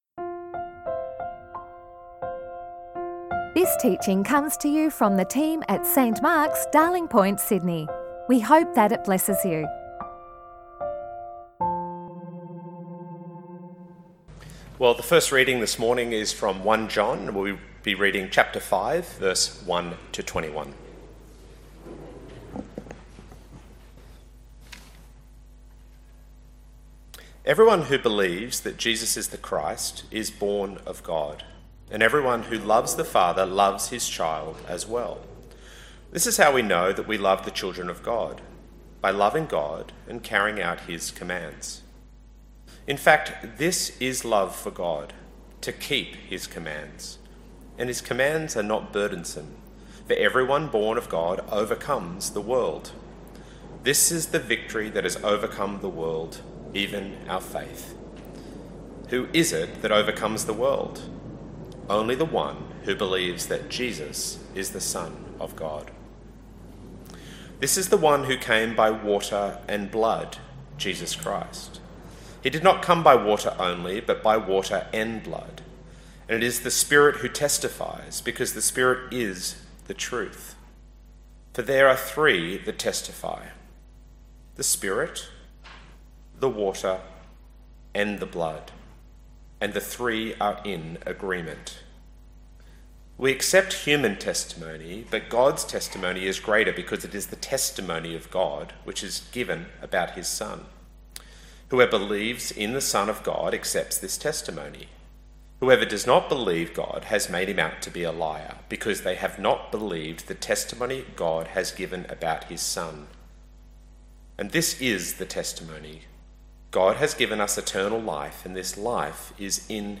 Weekly sermons recorded at St Mark's Darling Point in Sydney, Australia.